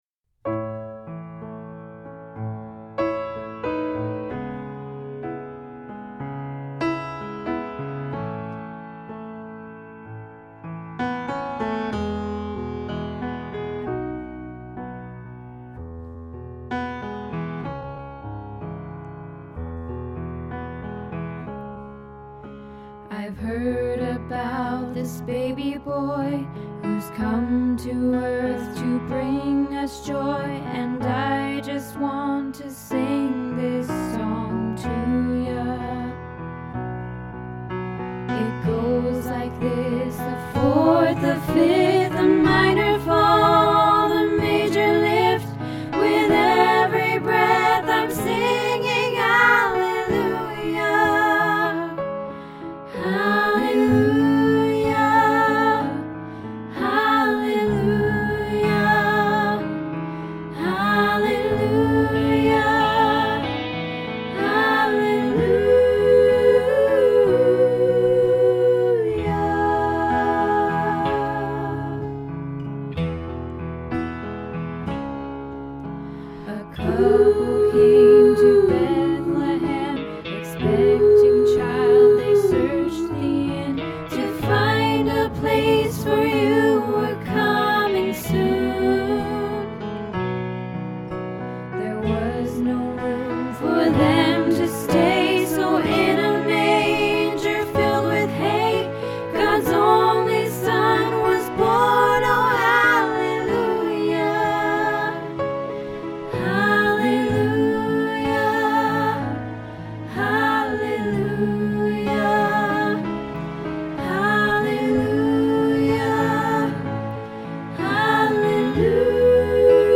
Hallelujah Christmas - Soprano